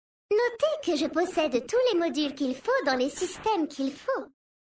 VF_Mecagnome_Femme_Flirt_Modules.mp3